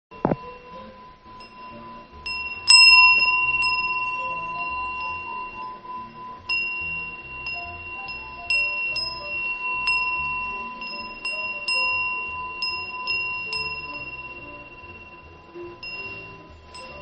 南部鉄風鈴大桜
サイズ 高さ約16cm（本体）箱入り（数量割引有り） 響きが違う南部風鈴 短冊のデザインは変わることがあります。